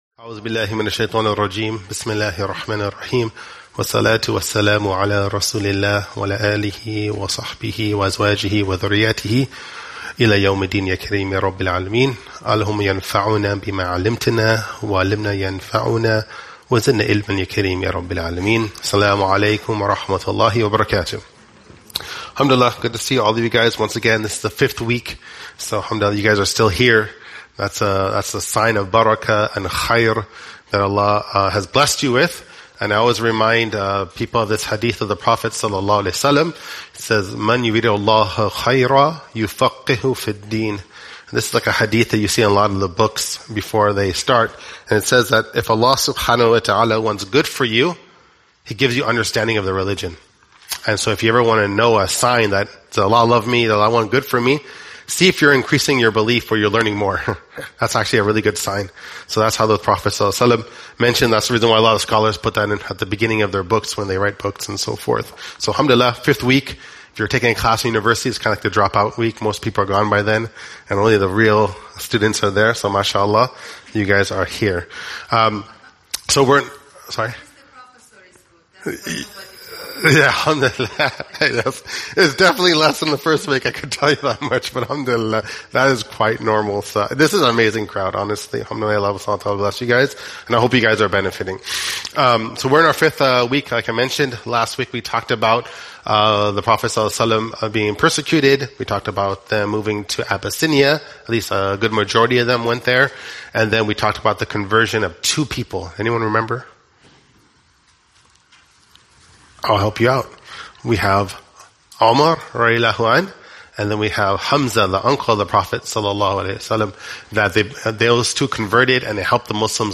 Seerah Class